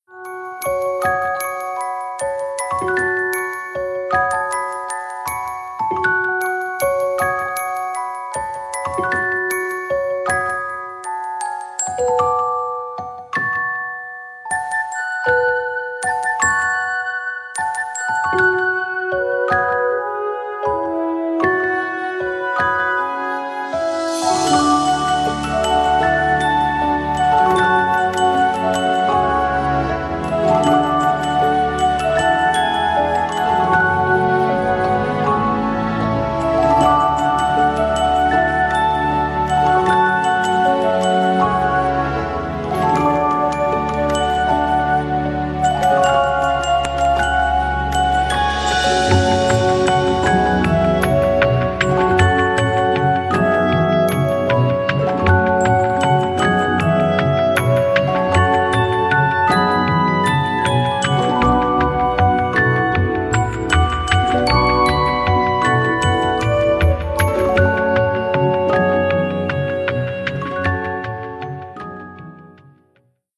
• Качество: 140, Stereo
спокойные
без слов
красивая мелодия
инструментальные
колокольчики
Музыкальная шкатулка
колыбельные